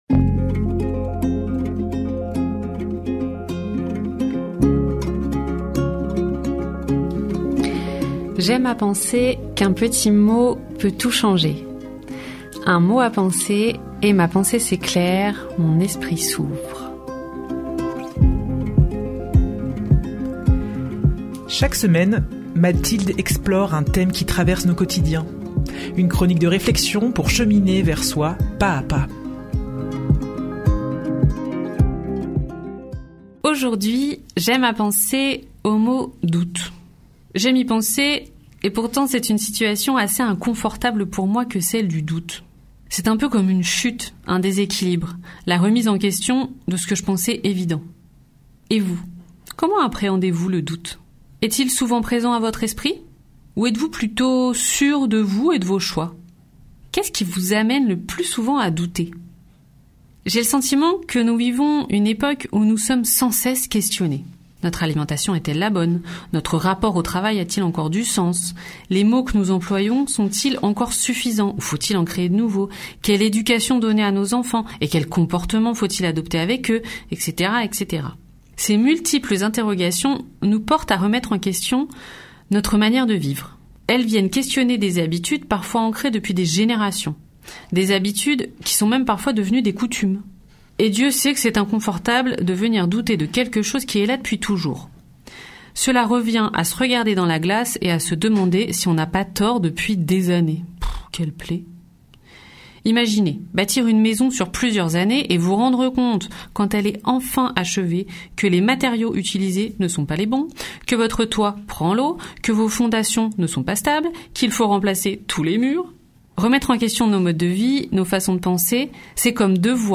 Une nouvelle chronique de réflexion pour cheminer vers soi pas-à-pas.